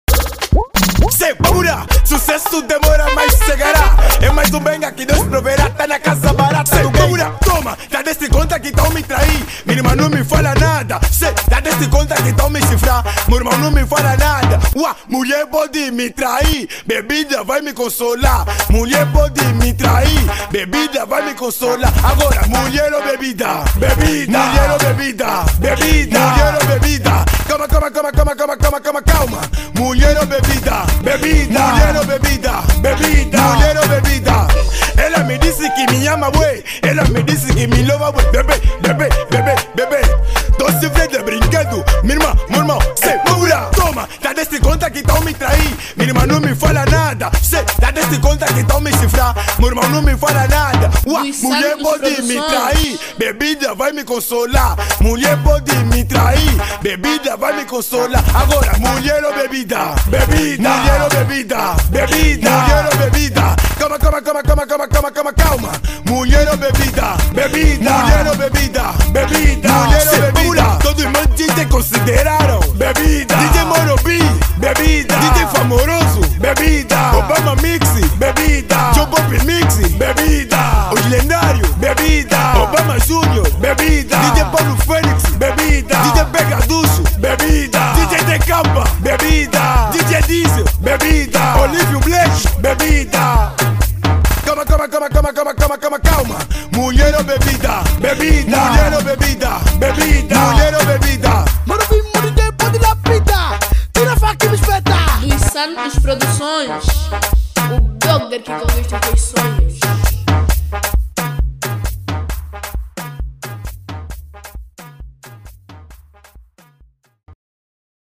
Categoria  Trap